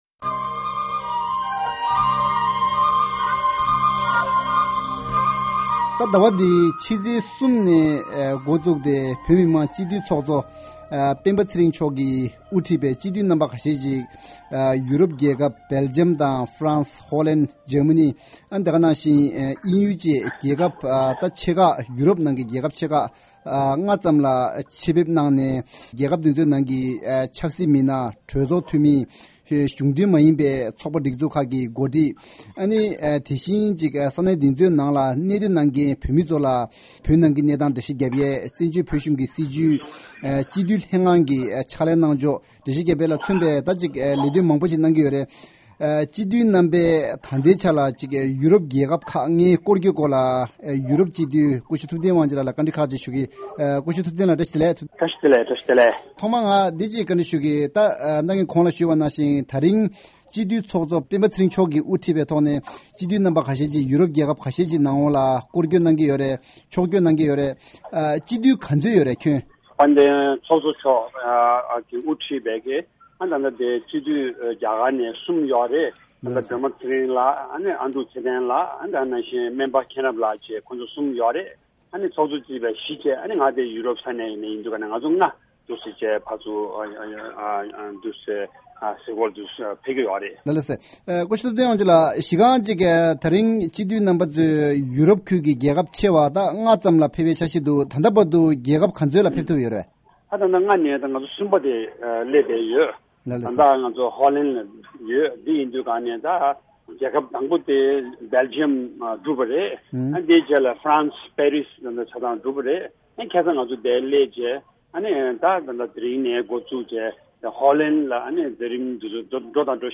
དེང་སྐབས་བོད་མི་མང་སྤྱི་འཐུས་ལྷན་ཚོགས་ཀྱི་ཚོགས་གཙོ་སྤེན་པ་ཚེ་རིང་ལགས་ཀྱིས་དབུ་འཁྲིད་དེ་སྤྱི་འཐུས་ཁག་ཅིག་རེ་ཡོ་རོབ་ཀྱི་རྒྱལ་ཁབ་ཆེ་བ་ལྔའི་ནང་ཆེད་ཕེབས་གནང་སྟེ་བོད་ནང་གི་ད་ལྟའི་གནས་སྟངས་ངོ་སྤྲོད་གནང་བཞིན་ཡོད་པ་དང་དུས་མཚུངས་ཡོ་རོབ་རྒྱལ་ཁབ་ ཁག་ནས་བོད་དོན་ཐད་རྒྱབ་སྐྱོར་སོན་ཐབས་སླད་ཞུ་གཏུགས་གནང་བཞིན་པ་རེད། བགྲོ་གླིང་མདུན་ལྕོག་ལ་རིམ་གྱིས་སྤྱི་འཐུས་རྣམ་པ་ཡོ་རབ་ཁུལ་དུ་ཆེད་ཕེབས་གནང་བའི་སྐོར་འབྲེལ་ཡོད་ཡོ་རོབ་སྤྱི་ འཐུས་སྐུ་ཞབས་ཐུབ་བསྟན་དབང་ཆེན་ལགས་སུ་གླེང་མོལ་ཞུ་གི་ཡིན།